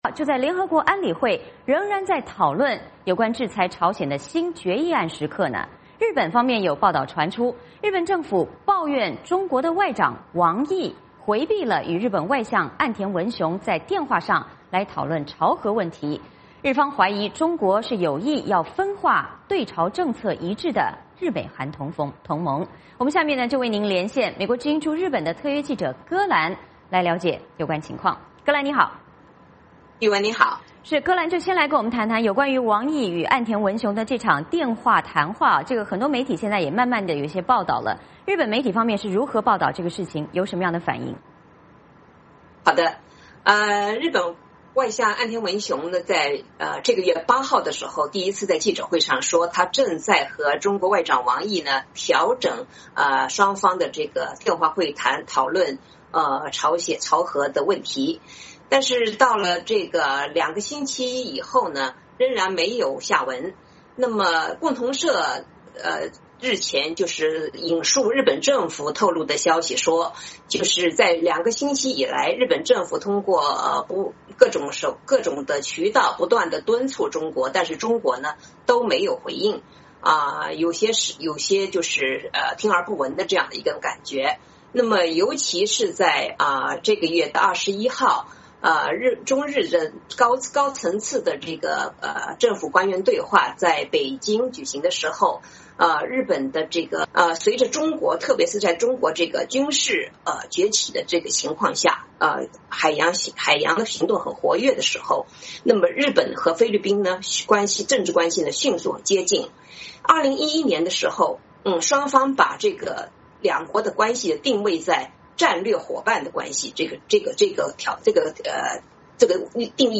嵌入 VOA连线：中日高层电话会 北京回避朝鲜核问题 嵌入 代码已经复制到剪贴板。